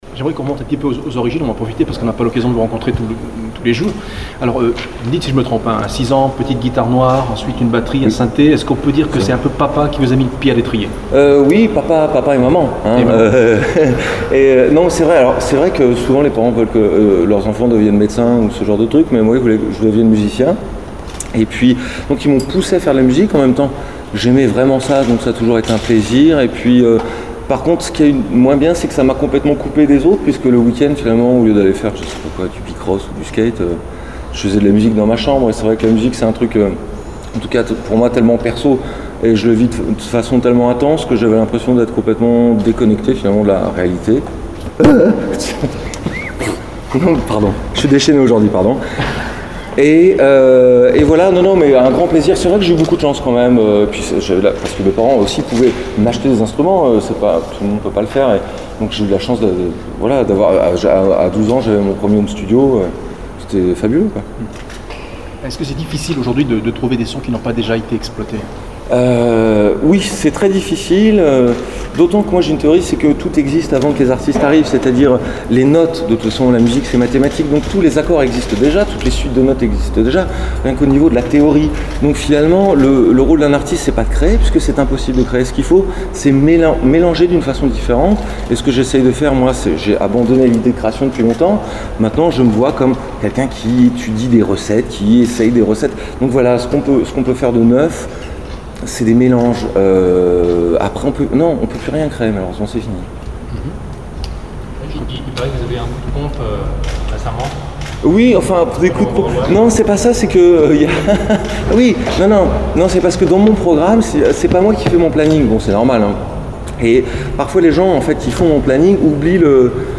Sébastien Tellier dans toute sa grandeur donnait une conférence de presse a l'occasion de son passage à Cannes pour le festival Pantiero.